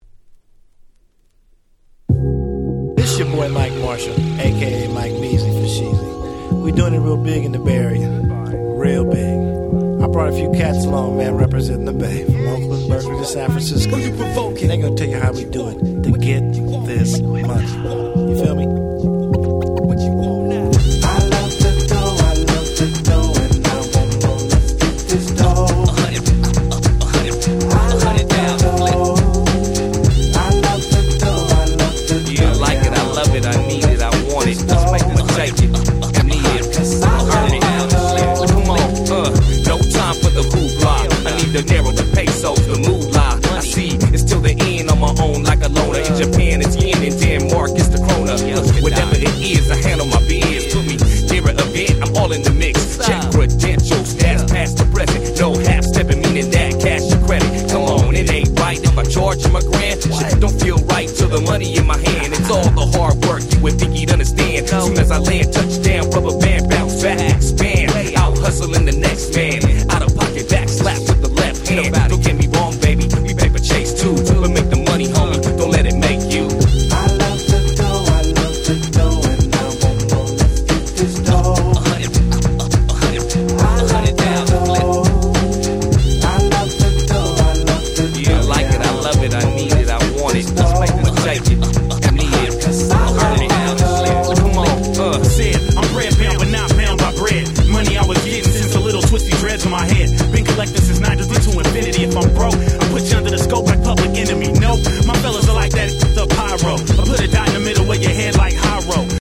04' Very Nice Chicano Hip Hop !!
キャッチー系 ウエッサイ G-Rap Gangsta Rap